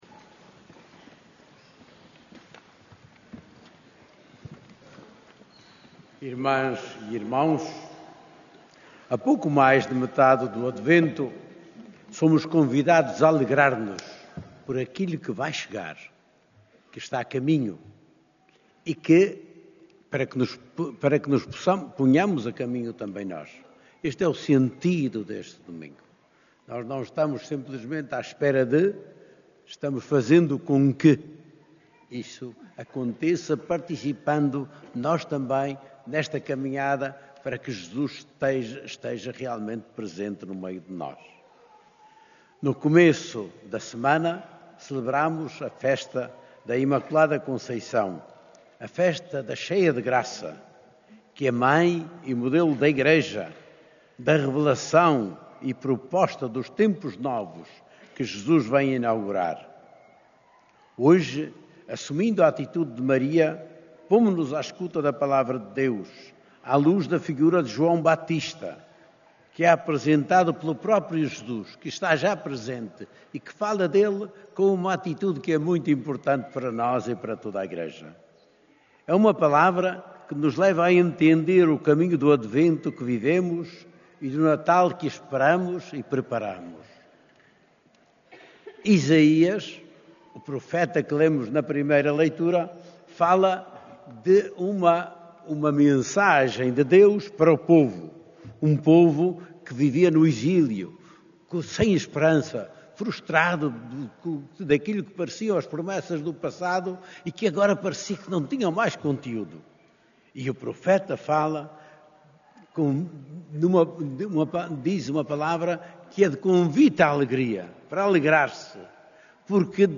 Na missa deste III Domingo do Advento, celebrada na Basílica da Santíssima Trindade, o bispo de Leiria-Fátima encorajou os fiéis a viverem a espera do Natal não com passividade, mas como um caminho de esperança ativa e compromisso concreto com a transformação do mundo.